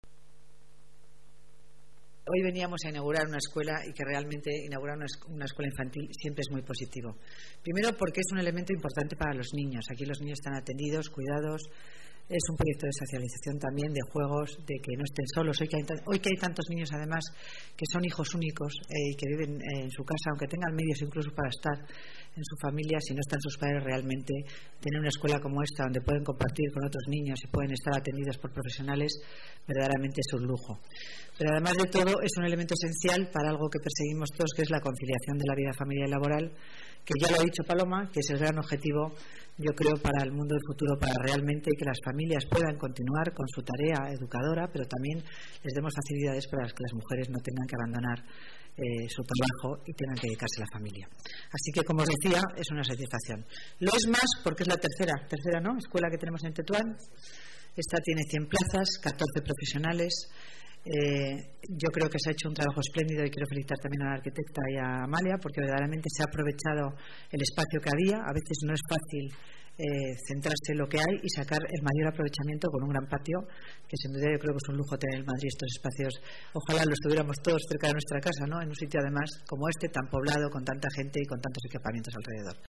Nueva ventana:Declaraciones delegada Servicios Sociales, Concepción Dancausa: escuela infantil Tetuán